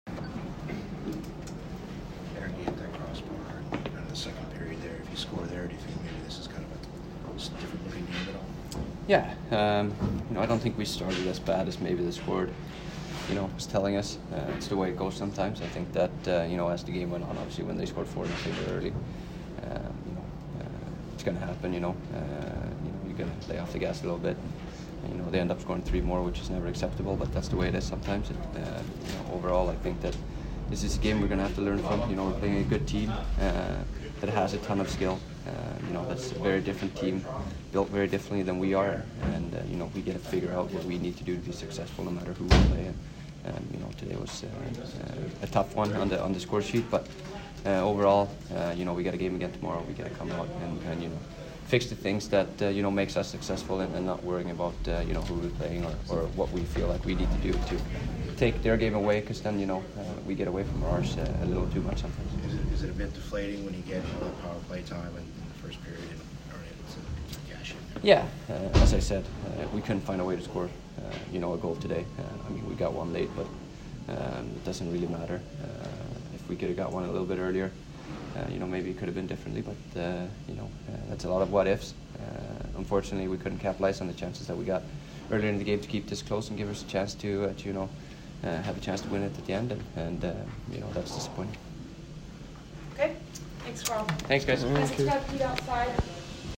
Erik Karlsson post-game 12/7